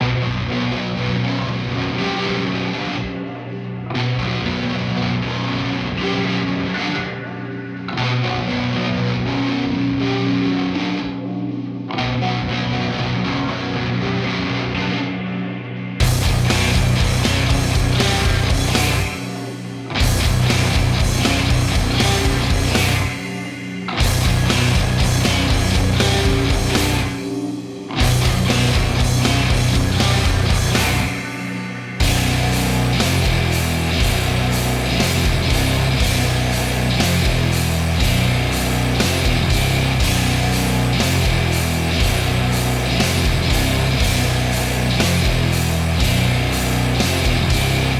a la stoner - тяжелячок с грязным звуком
Вот немного поэкспериментировал с грязным звуком, хотелось что-то вроде стоунера или не знаю как правильно даже назвать... Желание было сделать нечто тревожно-грязное :) имеет право на сущет\ствование или забить с этими экспериментами?